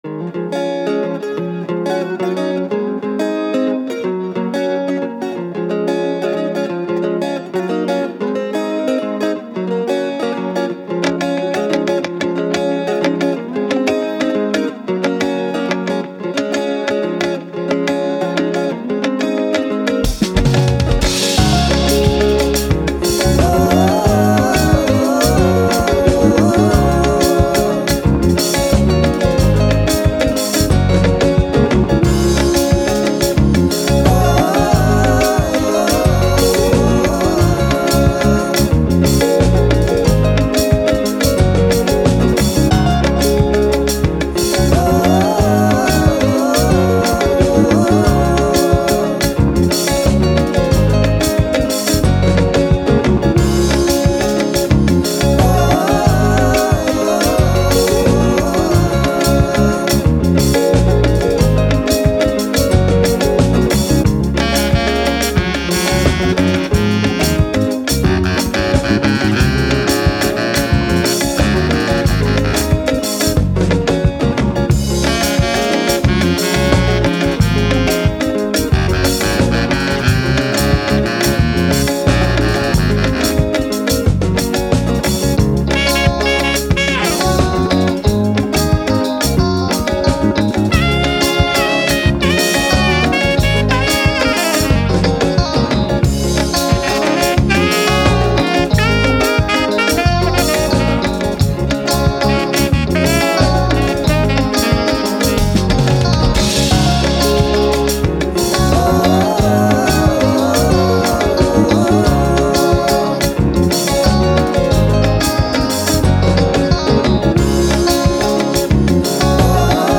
Afrobeat, Sun, Beach, Playful